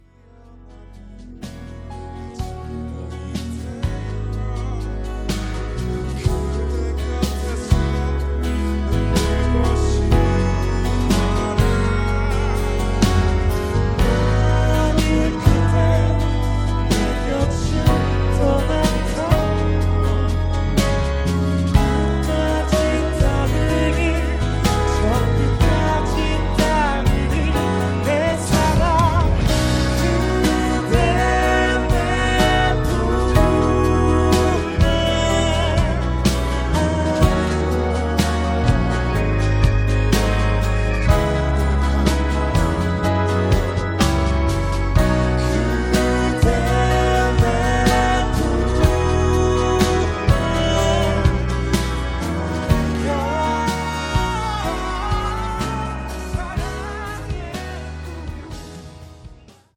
음정 -1키 6:19
장르 가요 구분 Voice MR